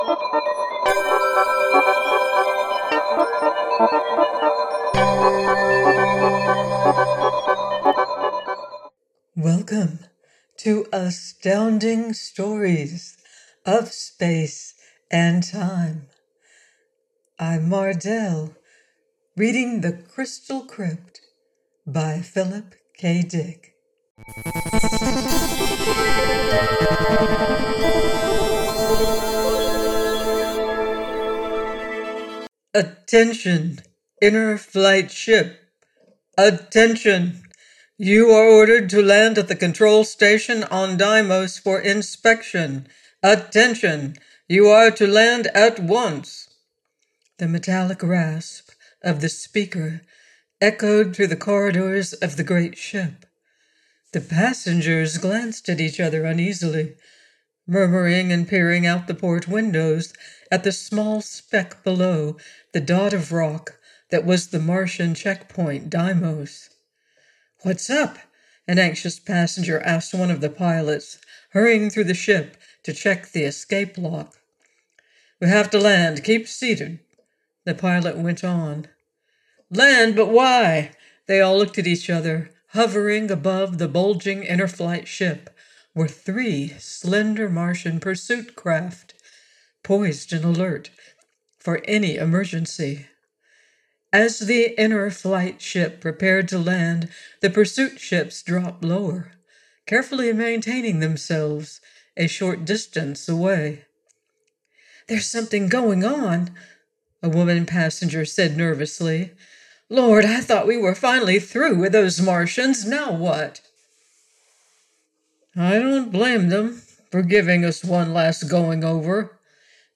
The Crystal Crypt by Philip K Dick - AUDIOBOOK